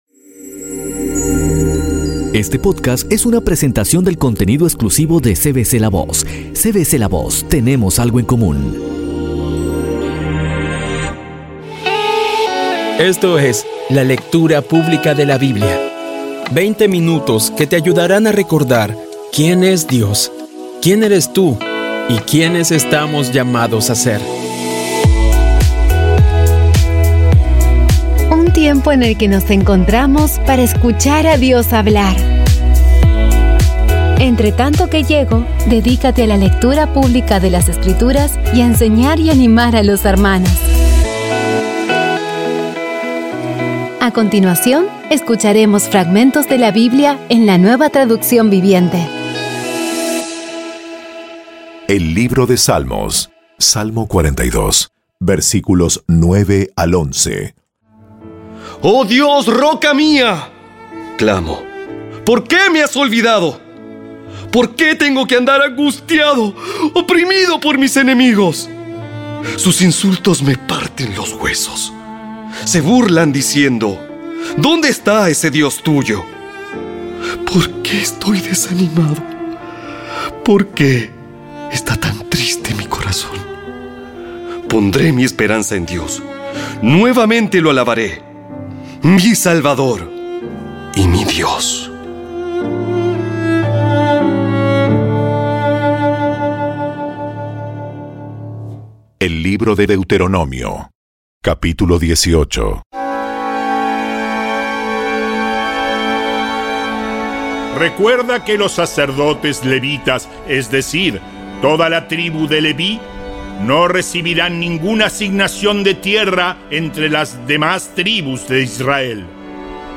Audio Biblia Dramatizada Episodio 94
Poco a poco y con las maravillosas voces actuadas de los protagonistas vas degustando las palabras de esa guía que Dios nos dio.